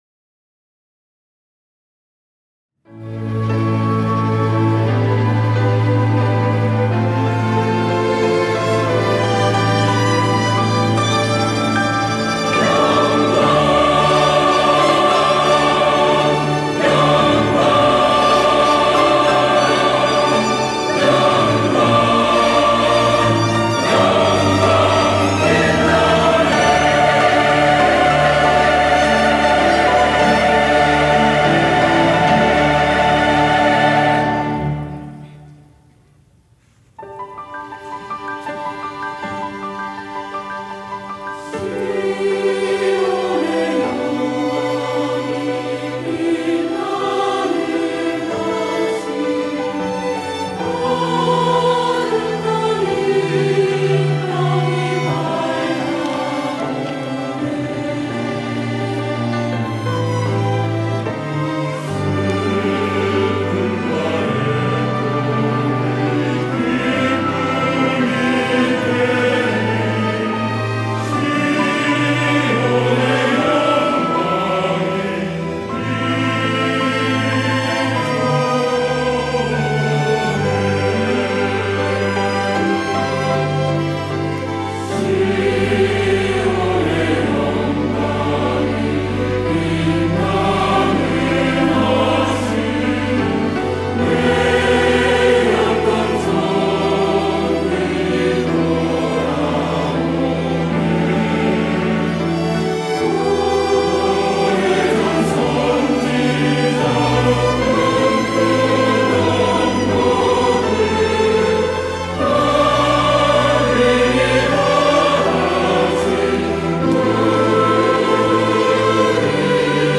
호산나(주일3부) - 시온의 영광이 빛나는 아침
찬양대